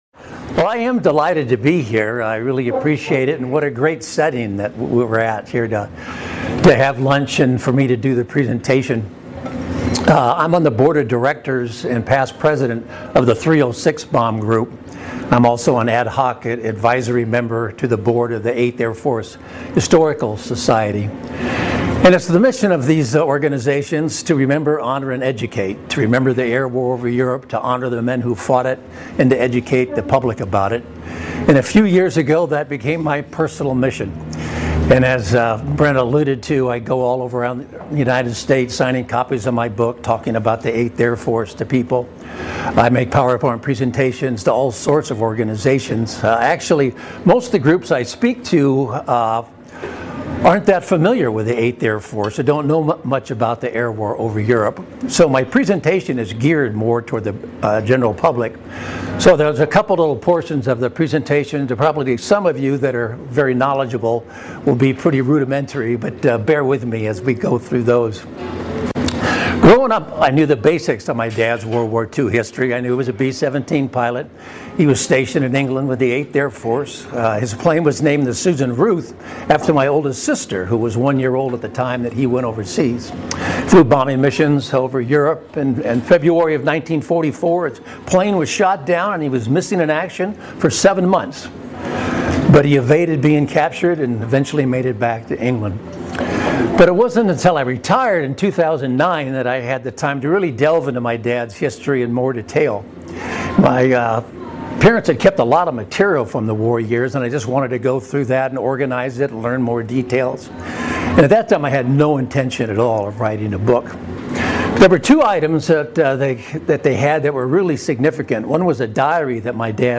presentation at The Eighth Air Force Historical Society, Georgia Chapter